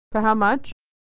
Unstressed 'your, you're' is reduced = /yər/